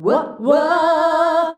UAH-UAAH E.wav